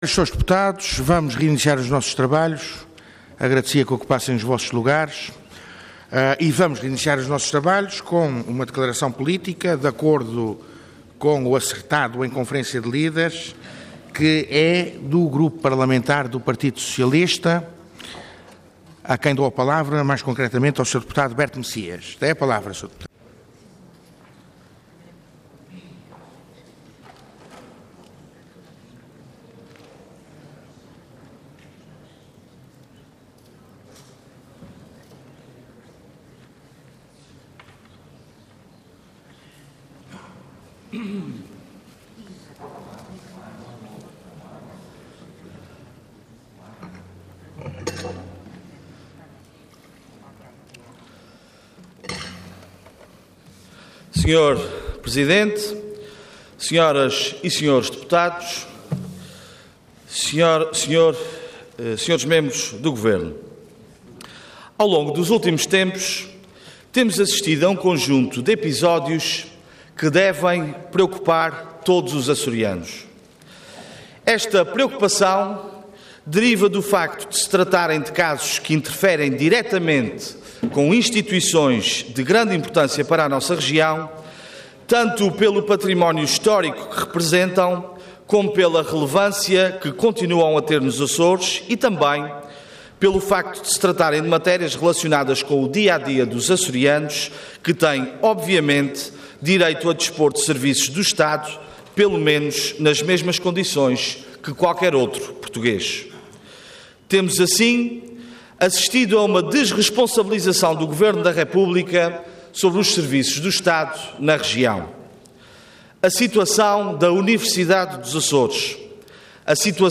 Parlamento online - Intervenção do Deputado Berto Messias do PS - Declaração Política - Desmantelamento das Funções do Estado na Região.
Declaração Política